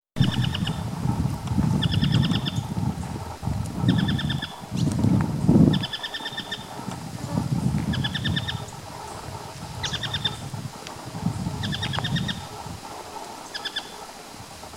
Tapaculo-ferreirinho (Scytalopus pachecoi)
Nome em Inglês: Planalto Tapaculo
Localidade ou área protegida: Reserva de Biosfera Yabotí
Condição: Selvagem
Certeza: Gravado Vocal